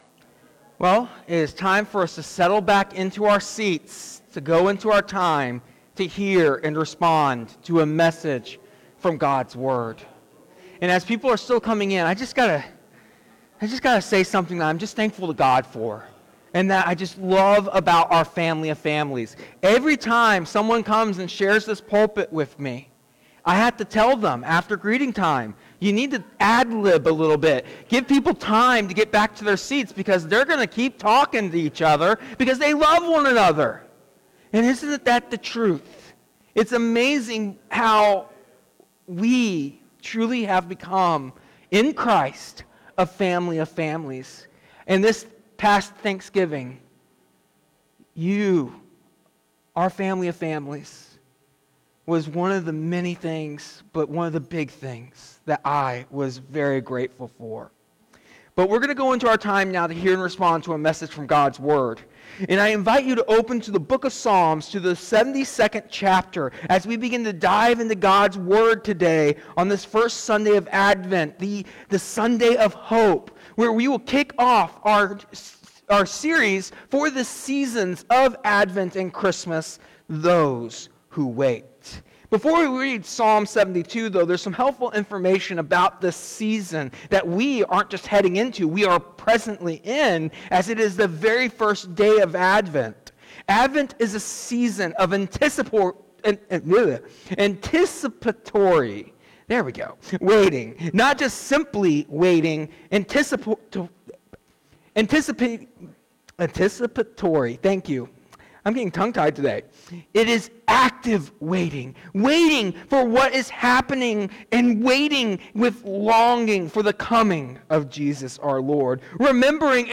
1 – What does it mean to wait with hope during the Advent season, according to the sermon, and how can we practice that in our daily lives?